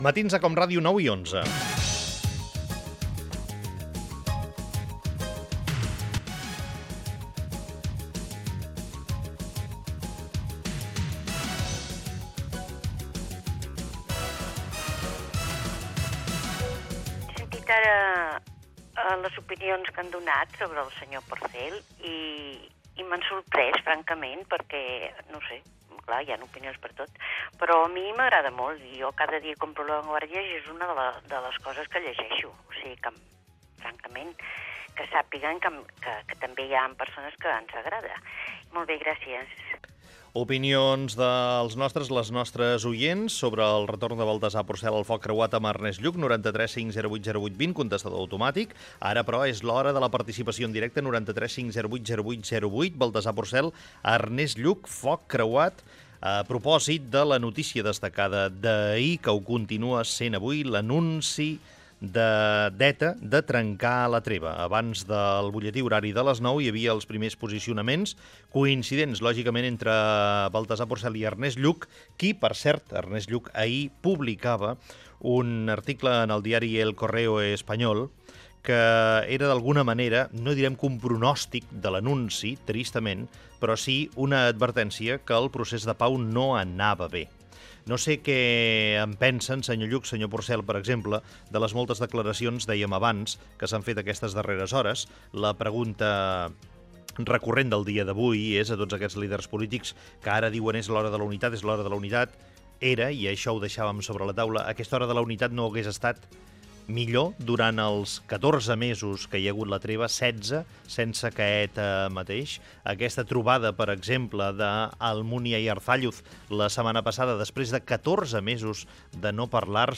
Gènere radiofònic Info-entreteniment